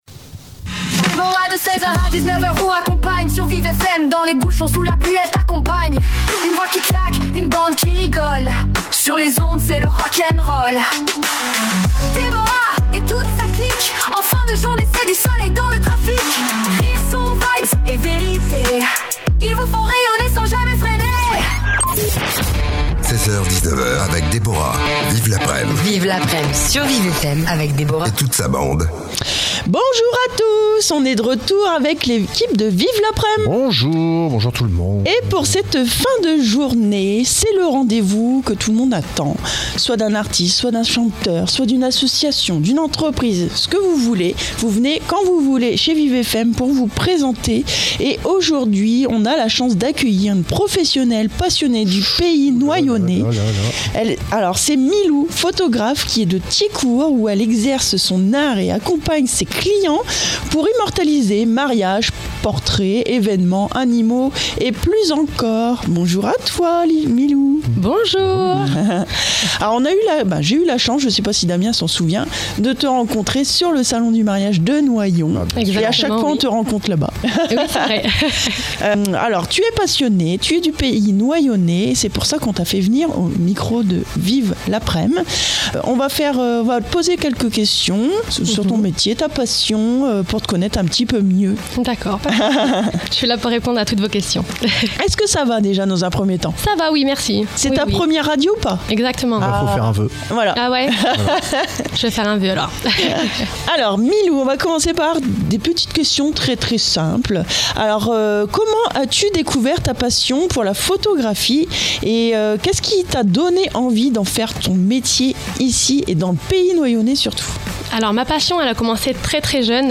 27 ITW